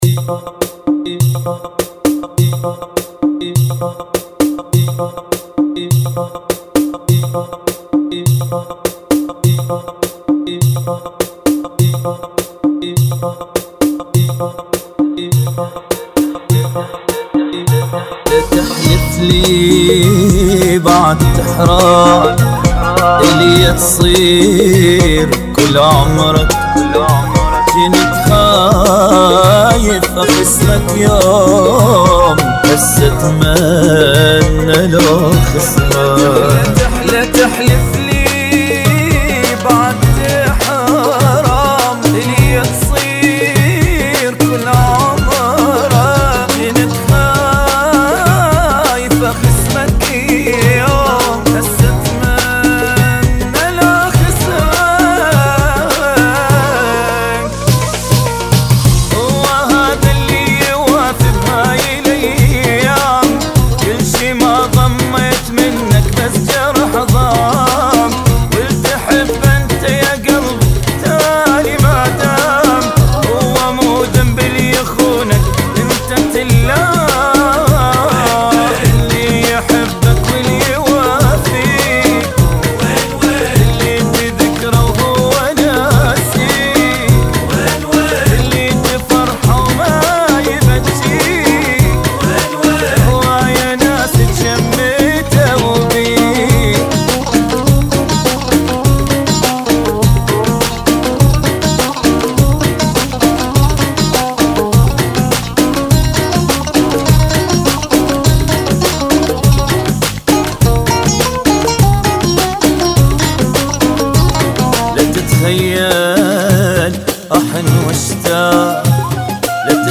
102 Bpm